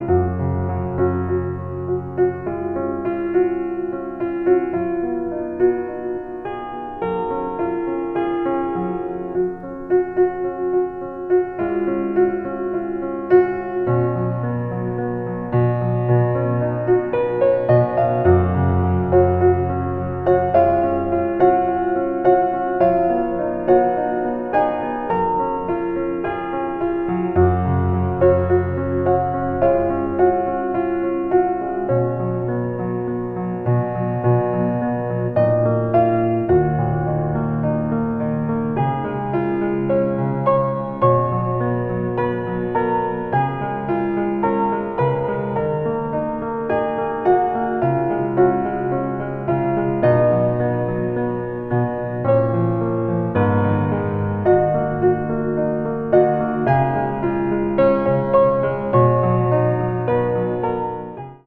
• Качество: 320, Stereo
грустные
спокойные
без слов
инструментальные
пианино
романтичные
нежные